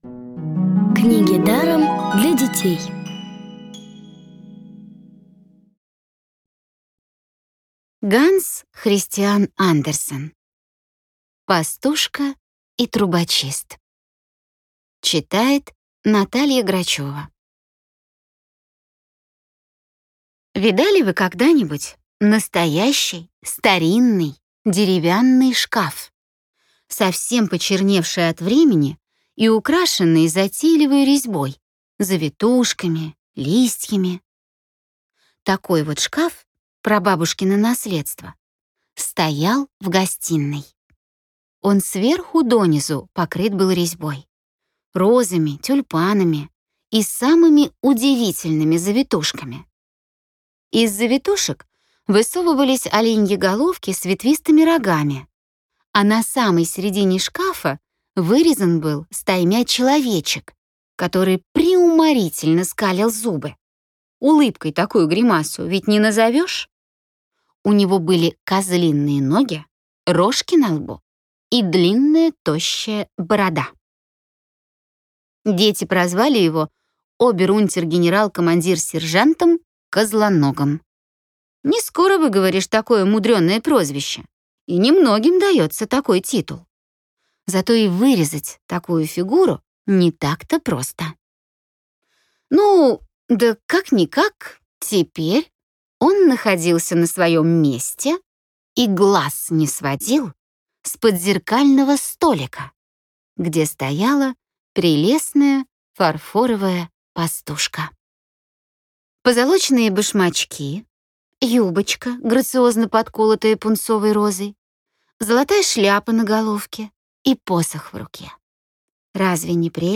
Аудиокниги онлайн – слушайте «Пастушку и трубочиста» в профессиональной озвучке и с качественным звуком. Ханс Кристиан Андерсен - Пастушка и трубочист.